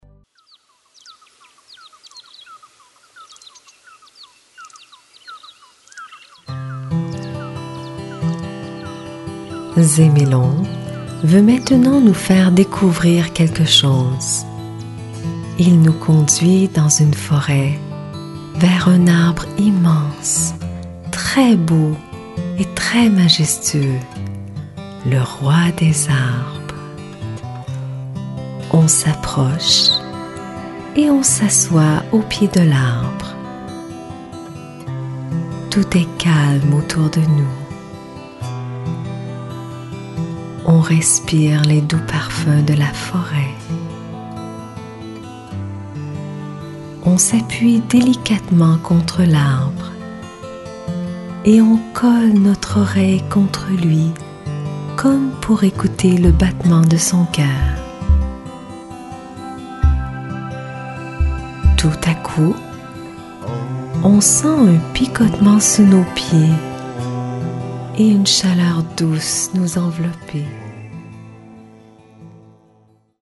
Visualisation guidée
En suivant la voix de la narratrice, l’enfant est emmené dans un monde magique, fait de beauté, de paix, de douceur et de joie. Au début de son aventure, il fait la connaissance du nain Zémélon, son guide au Pays de Naréha.